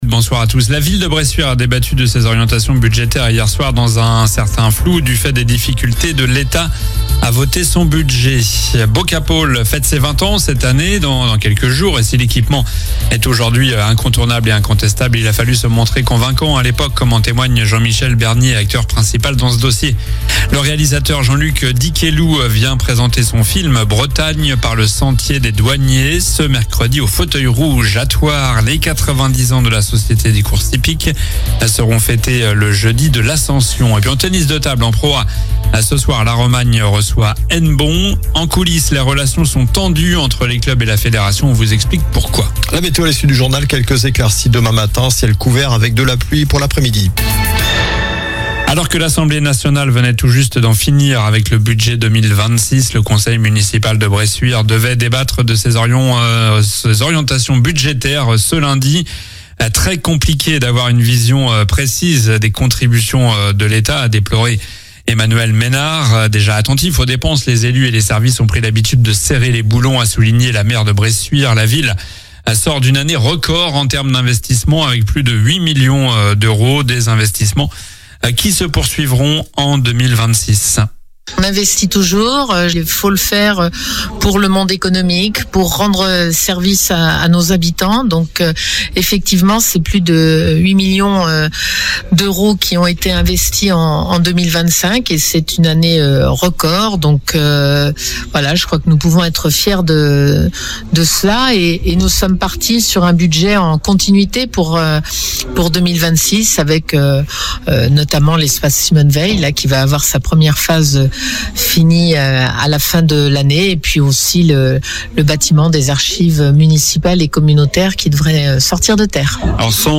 Journal du mardi 3 février (soir)